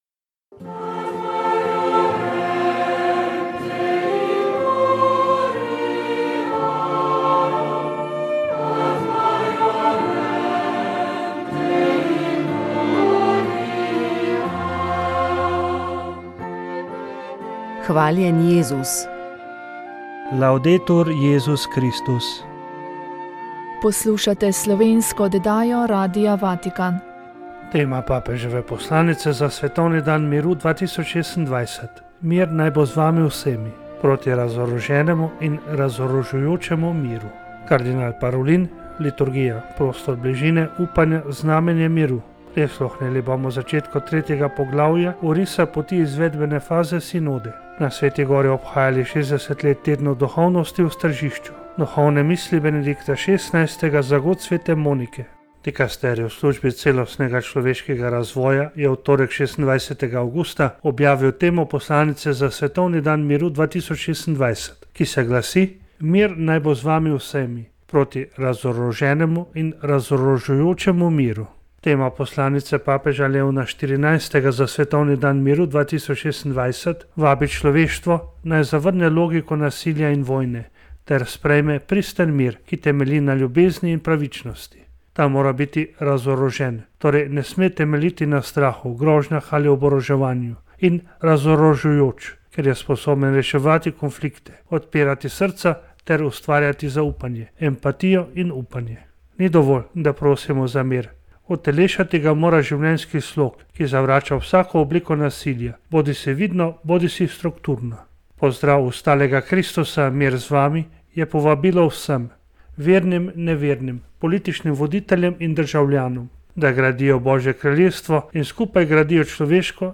Iz knjige smo za oddajo prebrali nekaj izbranih odlomkov.